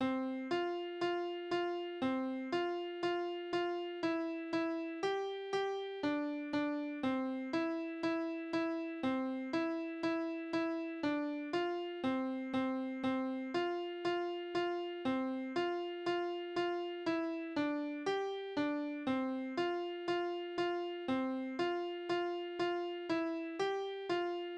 Kinderlieder: Bub und Spinne
Tonart: F-Dur
Tonumfang: Quinte